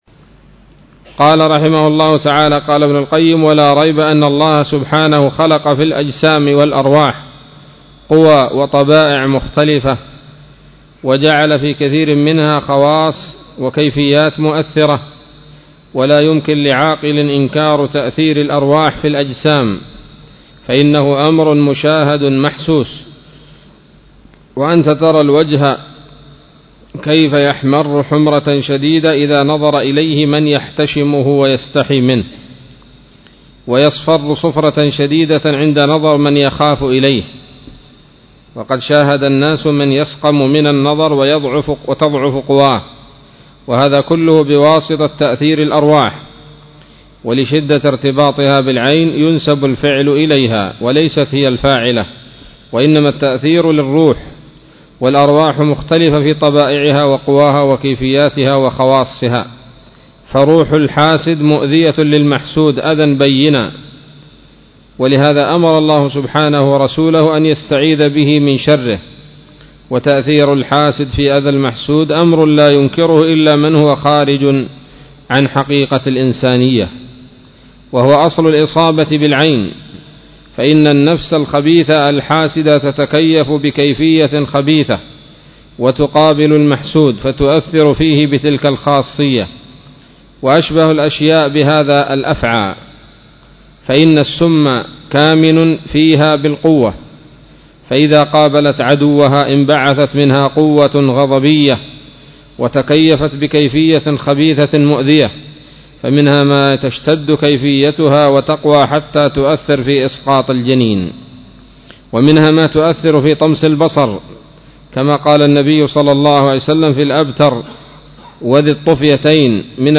الدرس السادس والأربعون من كتاب الطب النبوي لابن القيم